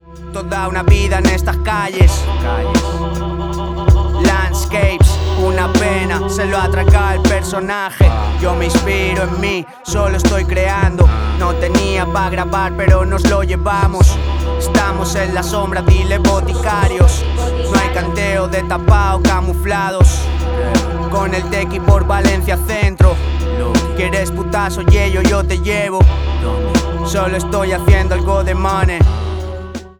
Hip Hop/Dj Tools